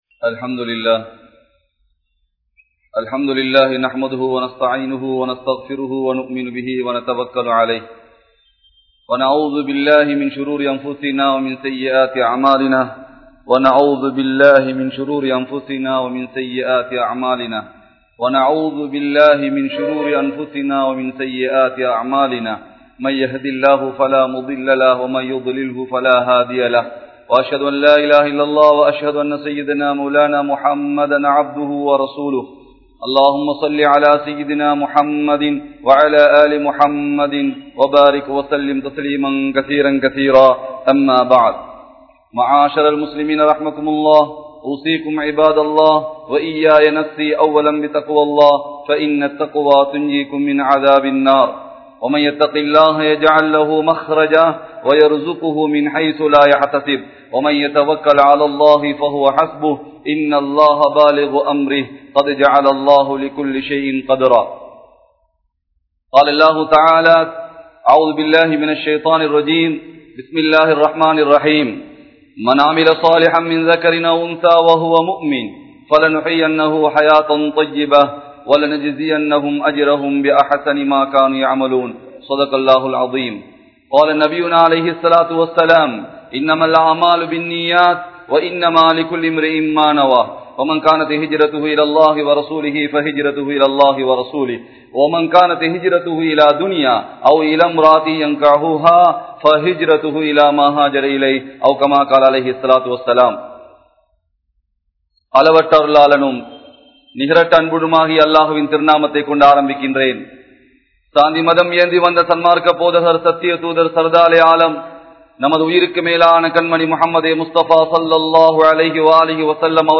Suvarkaththitku Kondu Sellum 06 Amalhal (சுவர்க்கத்திற்கு கொண்டு செல்லும் 06 அமல்கள்) | Audio Bayans | All Ceylon Muslim Youth Community | Addalaichenai
Colombo 12, Aluthkade, Muhiyadeen Jumua Masjidh